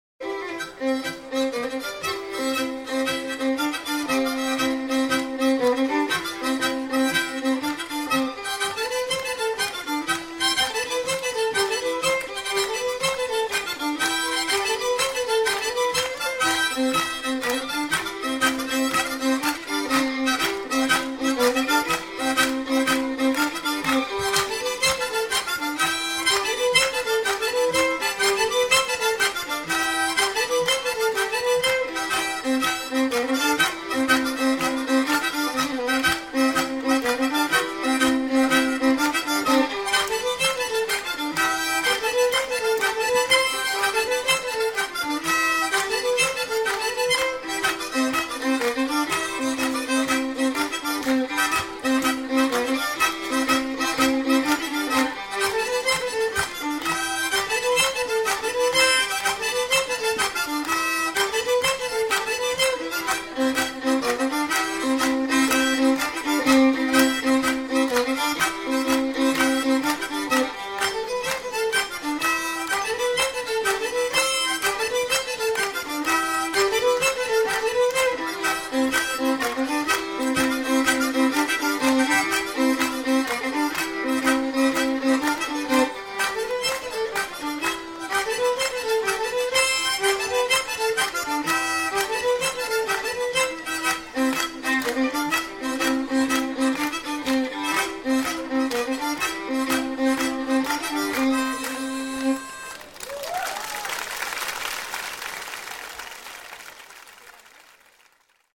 danse : gigue
circonstance : bal, dancerie
Pièce musicale éditée